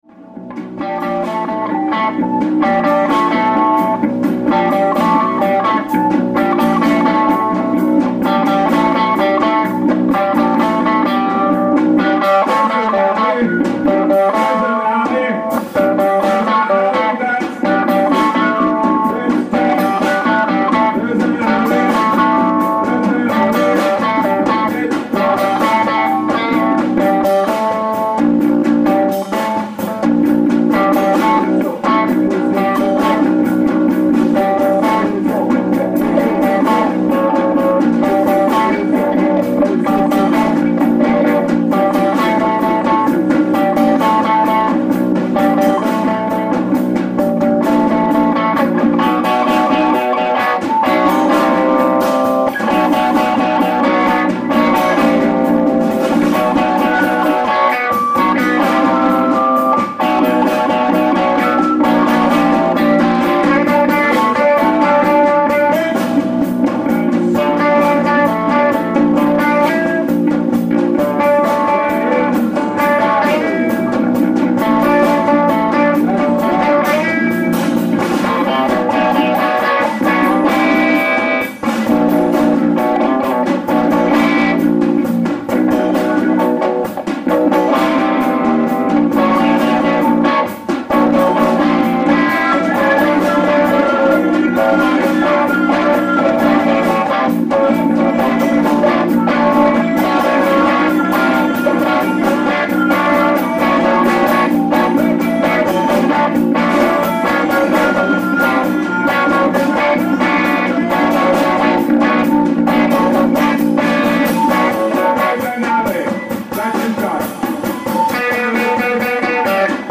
ALL MUSIC IS IMPROVISED ON SITE
voice/bass
guitar
sax
drums